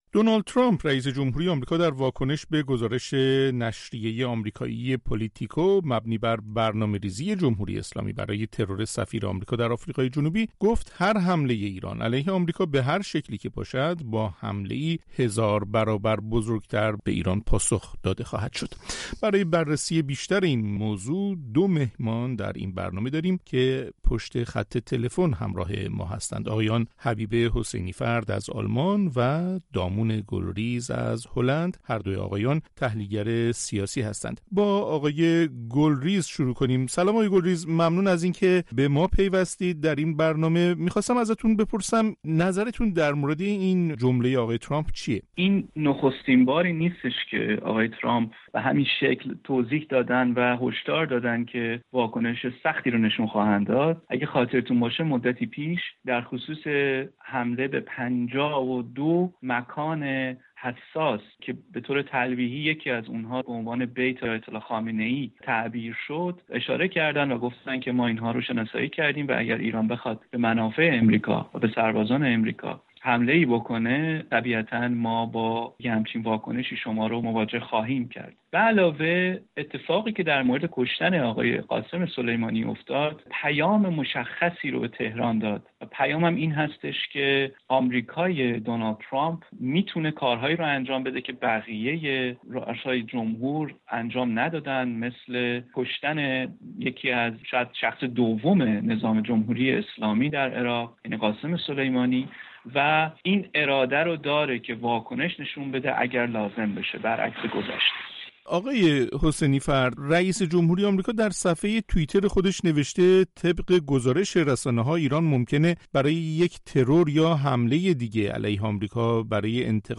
دو تحلیلگر سیاسی